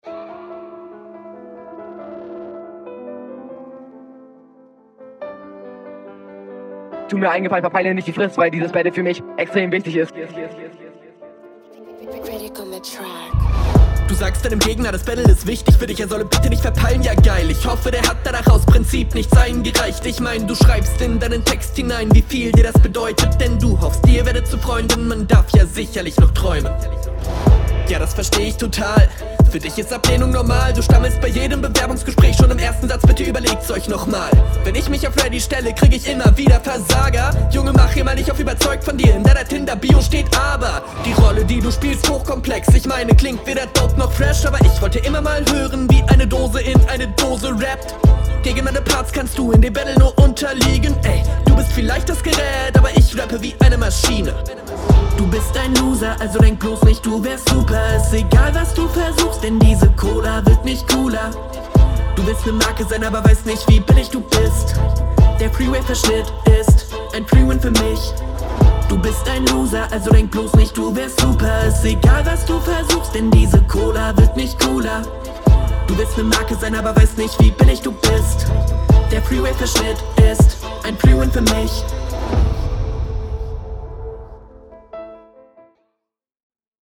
Battle Rap Bunker
Niederklassiges Battle